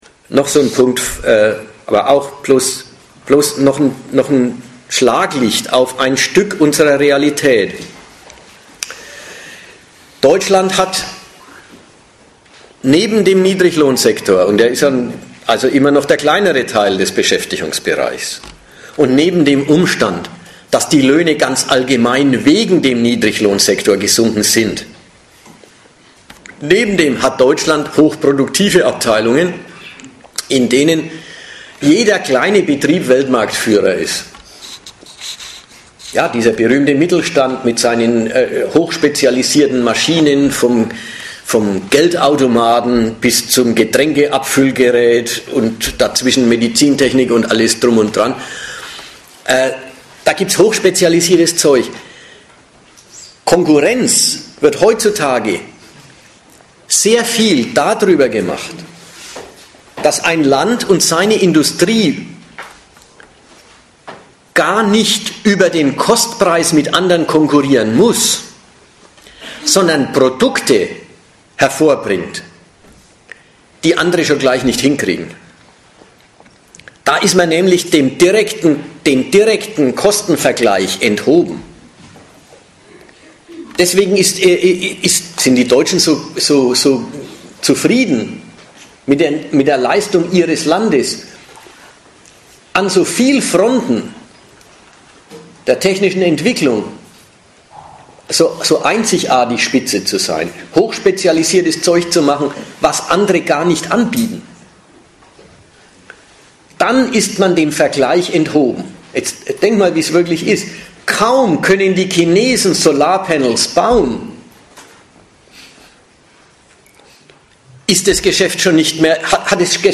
Datum 07.05.2013 Ort Berlin Themenbereich Arbeit, Kapital und Sozialstaat Veranstalter KK Gruppe Berlin Dozent Gastreferenten der Zeitschrift GegenStandpunkt Arbeit und Reichtum scheinen eine klare Beziehung zu haben: Die Arbeit schafft den Reichtum.